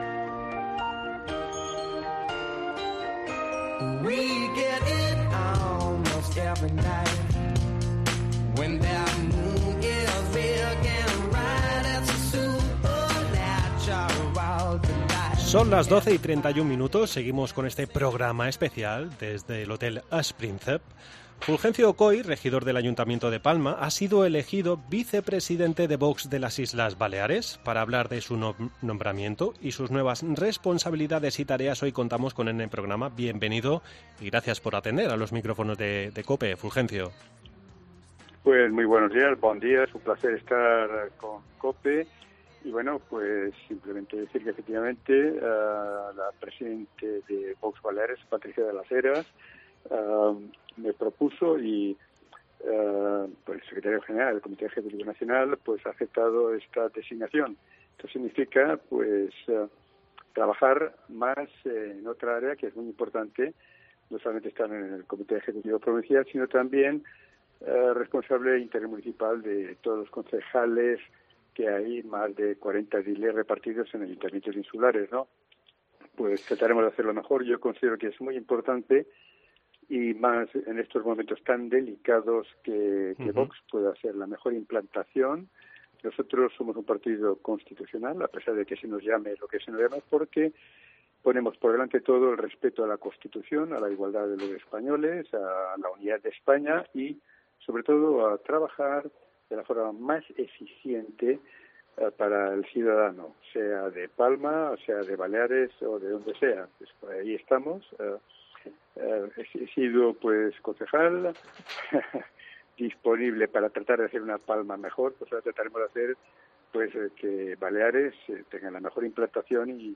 AUDIO: Tras el recién nombramiento de Fulgencio Coll como vicepresidente de VOX en Baleares hablamos con él para conocer sus nuevos proyectos y...
Fulgencio Coll, regidor del Ayuntamiento de Palma ha sido elegido vicepresidente de VOX de las Islas Baleares. Para hablar de su nombramiento y sus nuevas responsabilidades y tareas hoy contamos con con él en el programa.